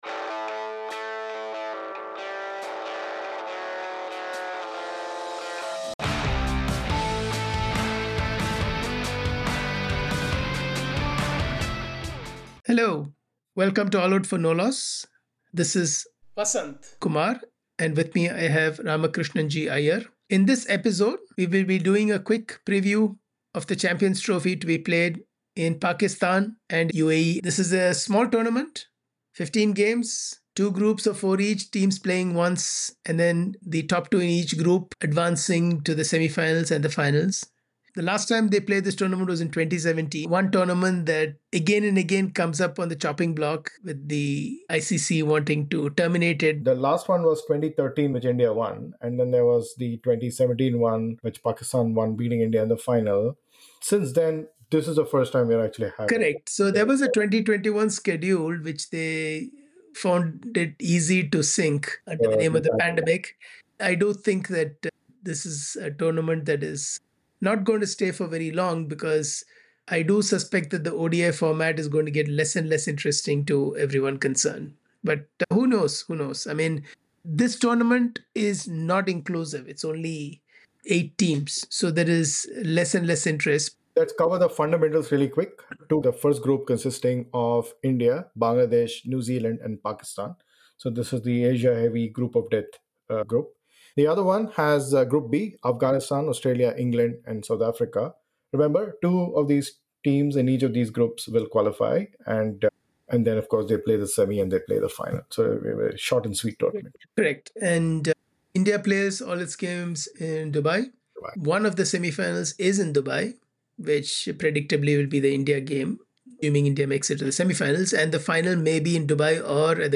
In this conversation we will be taking a look a brief look at the squads of the 8 teams and trying to assess their prospects.